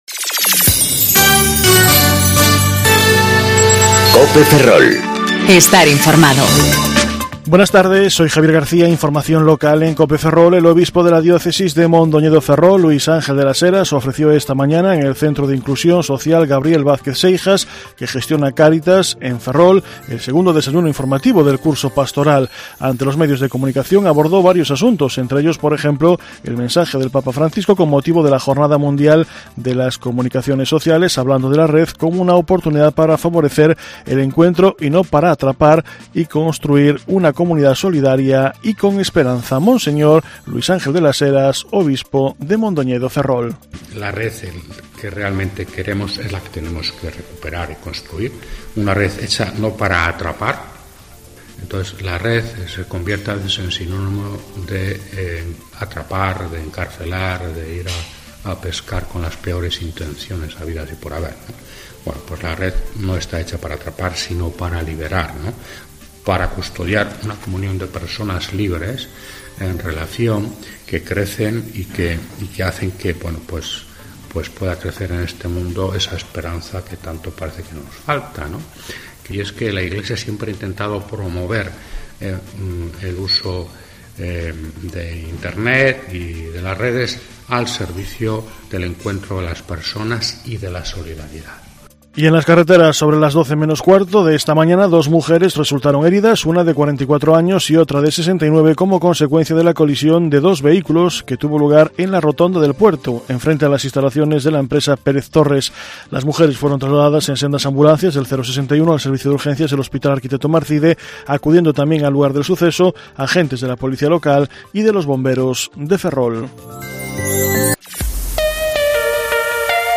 Informativo Mediodía Cope Ferrol 31/05/2019 (De 14.20 a 14.30 horas)